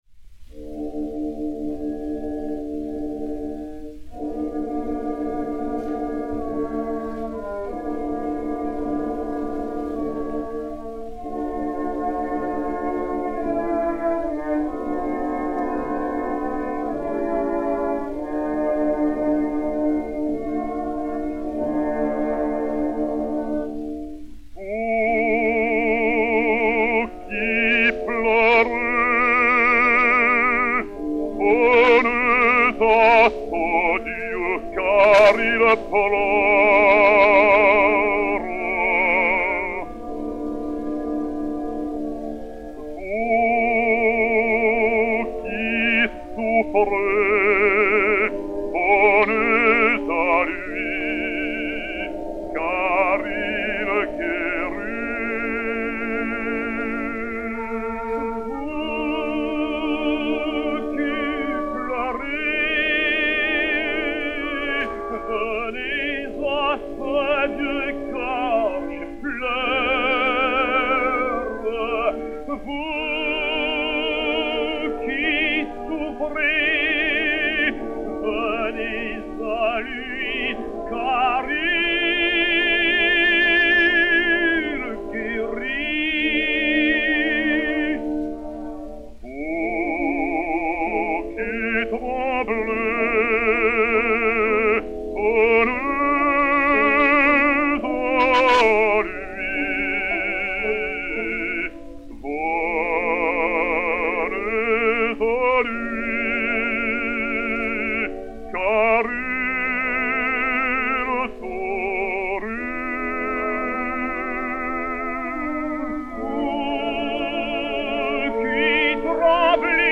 Chant religieux à deux voix